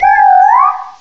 cry_not_yamask.aif